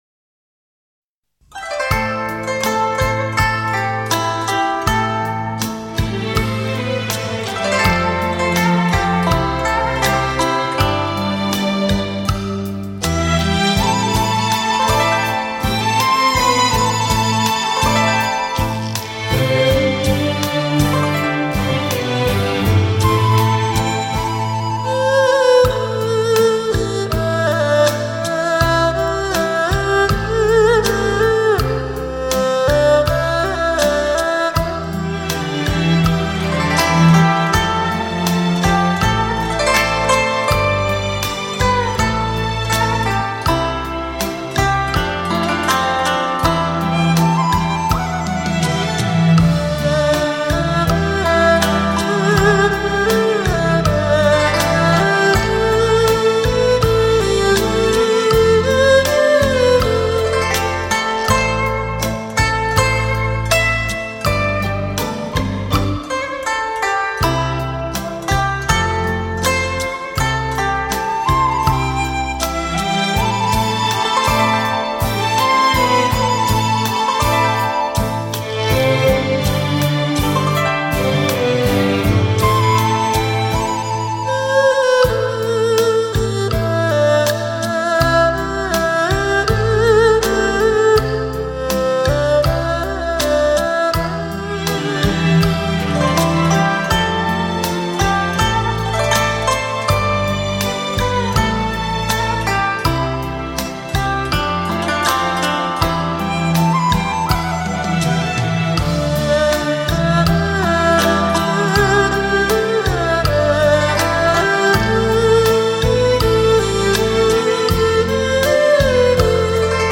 古筝、二胡、笛子、杨琴、小提琴与乐队的缠绵交响协奏乐章